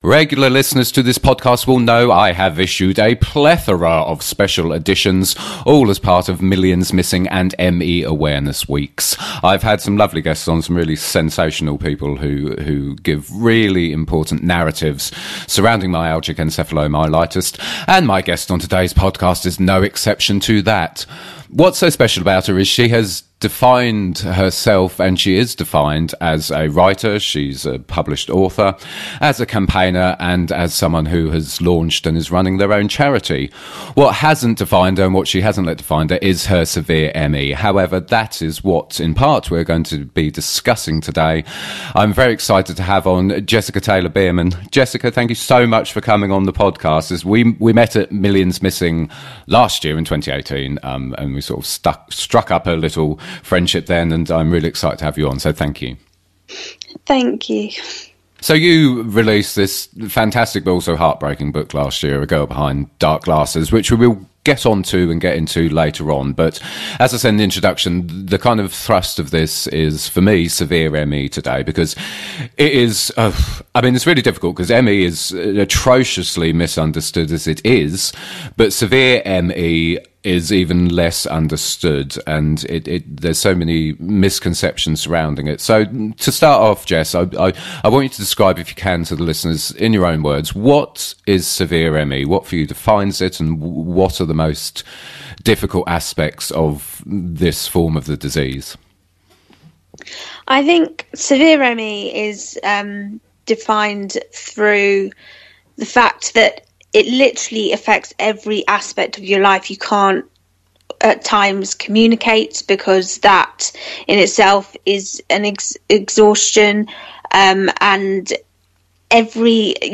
Below, you can find each of the episodes with just the interviews only. Because many people living with ME have both aural sensitivity and cognitive impairment, it’s often important to them to have just talking without any external sources, like music. So here are my stripped back podcasts.